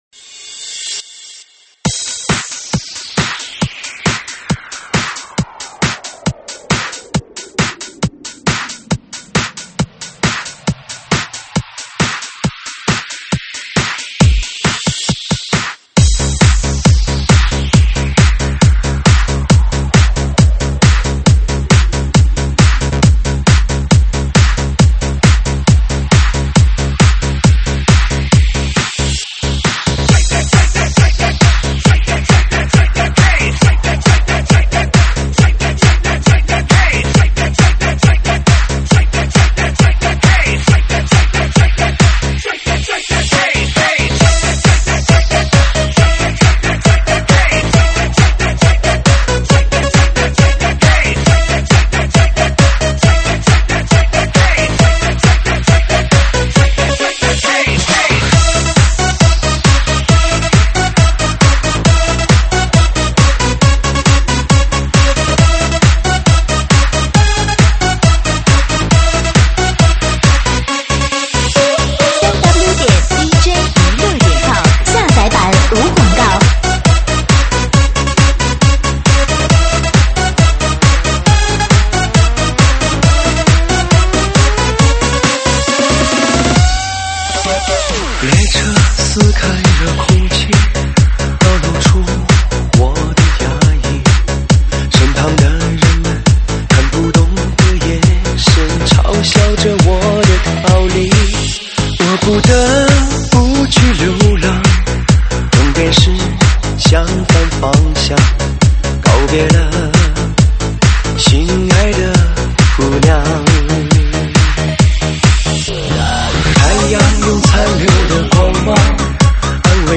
【劲爆风格】车载音乐REMIX上传于：2025-05-08 05:22，收录于(现场串烧)提供在线试听及mp3下载。